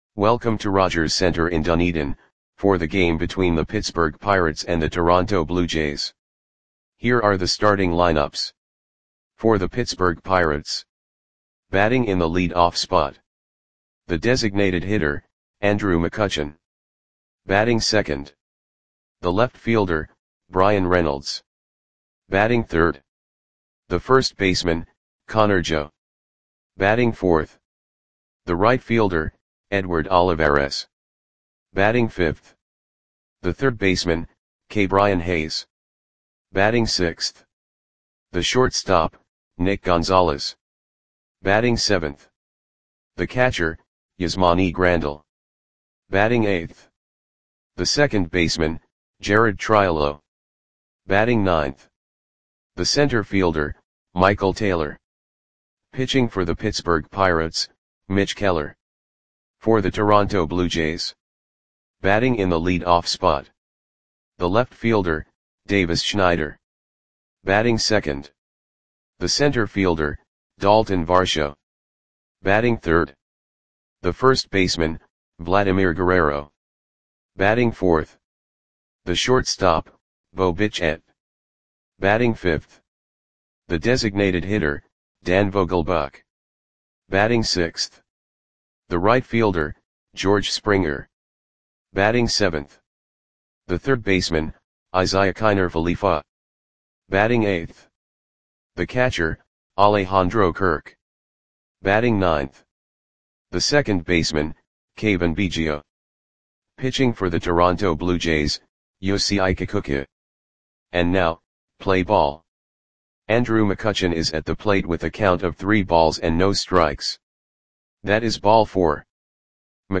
Audio Play-by-Play for Toronto Blue Jays on June 1, 2024
Click the button below to listen to the audio play-by-play.